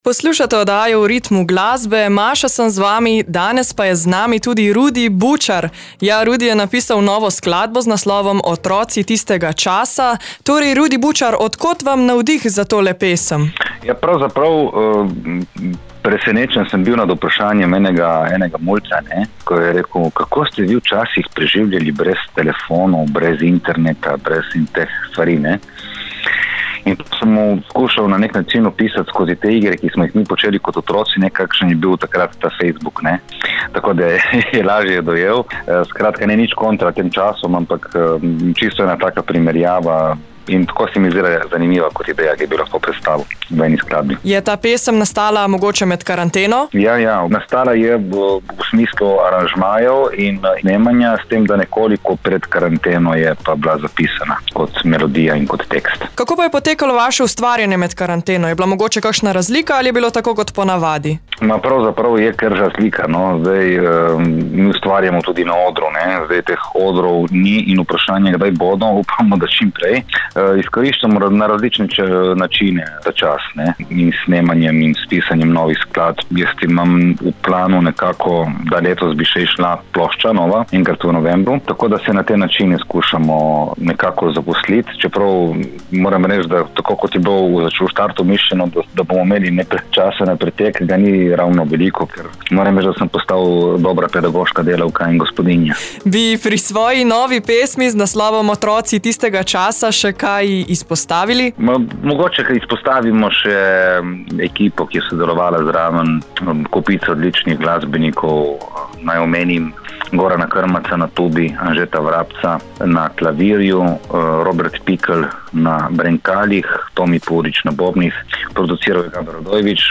” Odgovor dobite v pogovoru in pesmi.